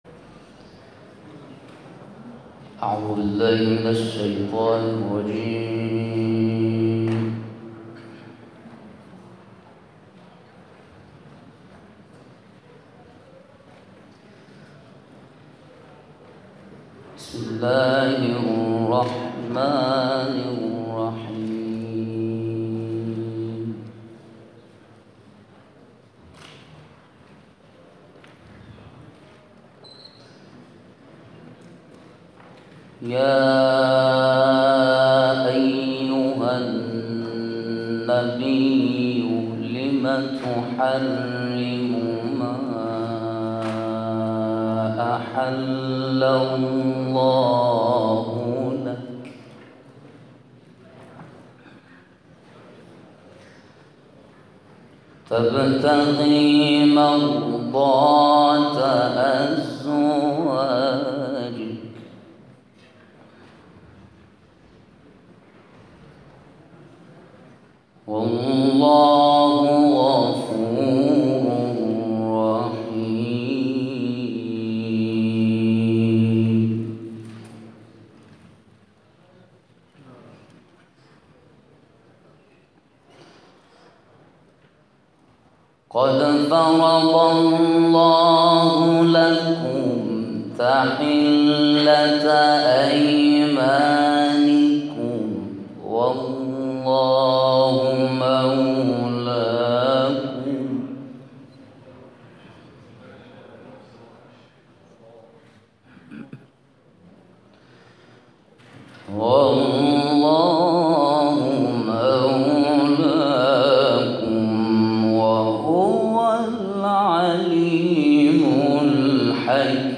این تلاوت سیزدهم اردیبهشت‌ماه در شورای عالی قرآن اجرا شده است و مدت زمان آن 17 دقیقه است.